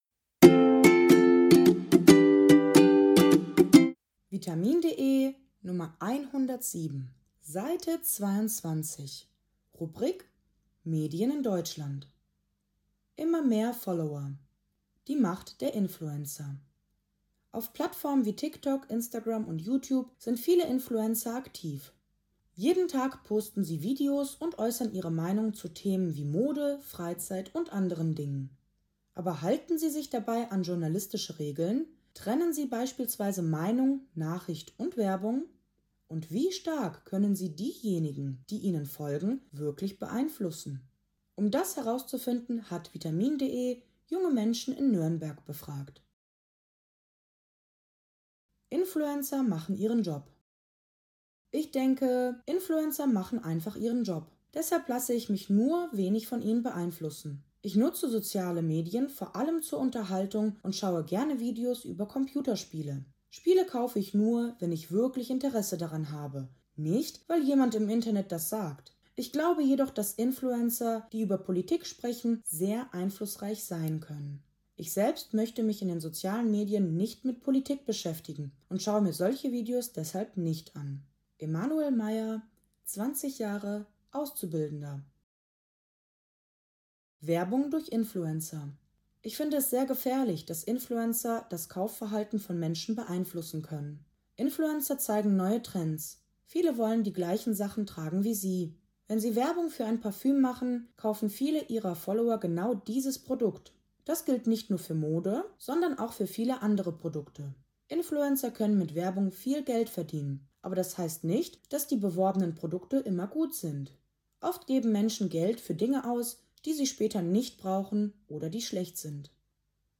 Audiodatei (Hörversion) zum Text
Intro-Melodie der Audios